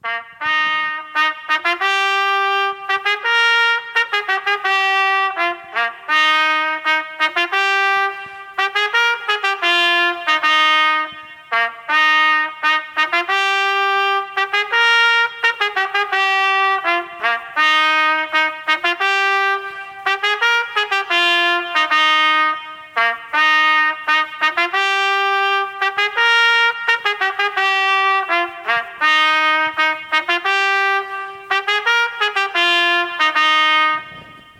Mowa o hejnale, który już w najbliższy wtorek, 14 października po raz pierwszy w samo południe rozbrzmi z włoszczowskiego ratusza.
Grzegorz Dziubek, burmistrz Włoszczowy informuje, że hejnał to dźwiękowy symbol, który ma promować gminę oraz wzbogacać jej tradycje kulturalne.
Hejnal-online-audio-converter.com_.mp3